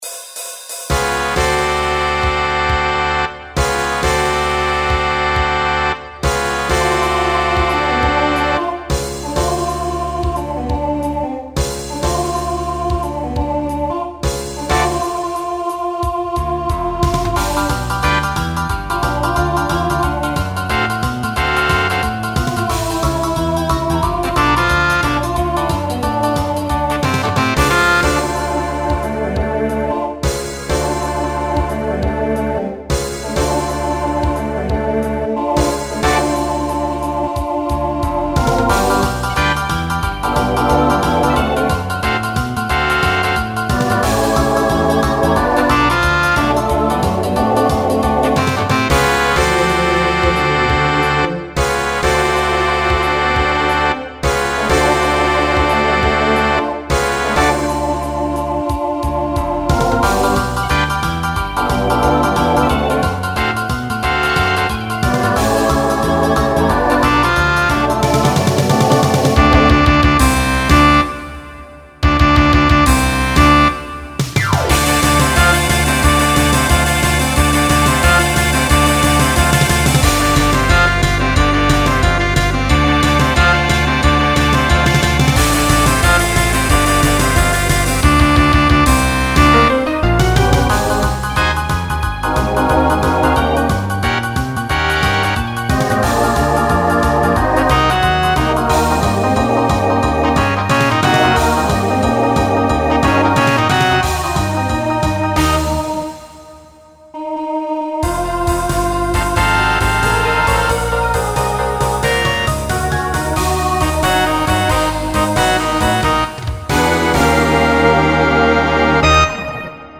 Transition Voicing SATB